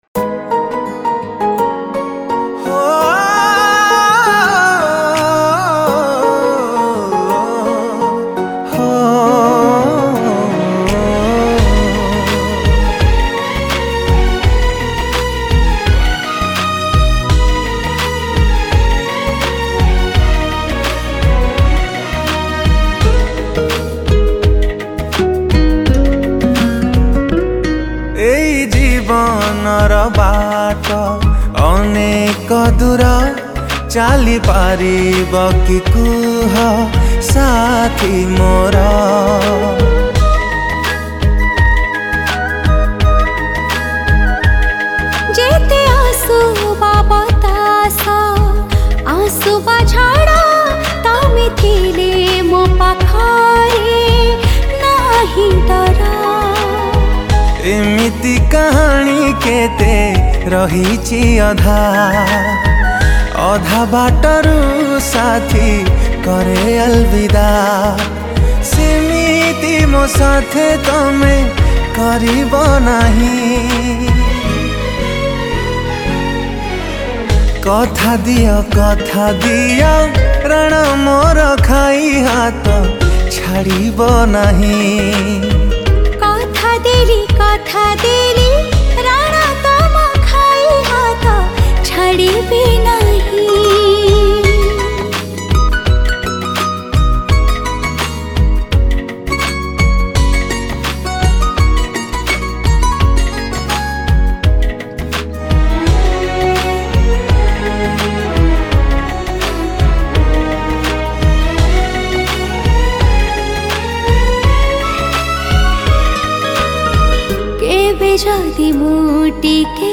Song Type :Romantic Song